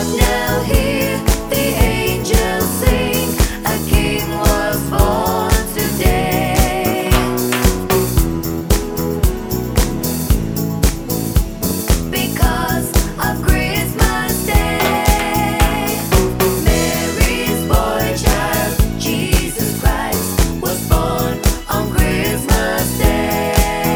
With Ad Libs Disco 4:00 Buy £1.50